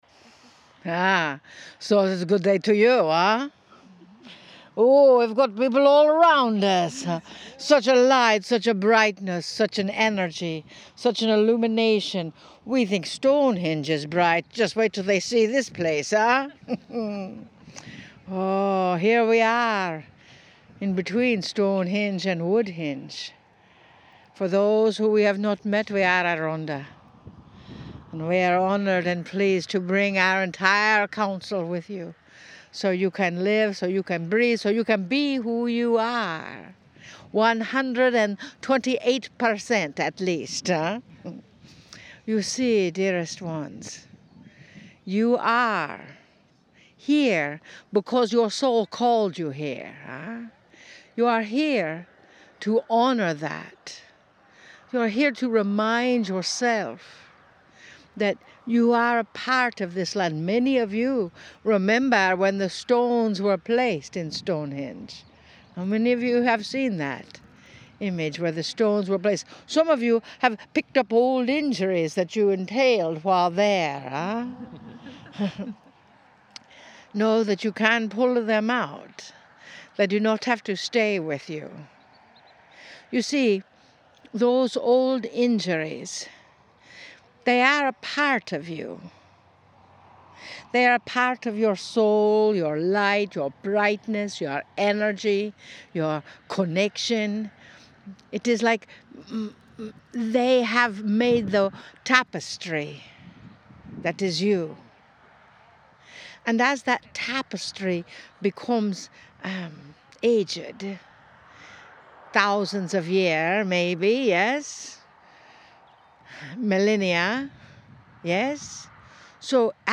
MEDITATION & CHANNELLING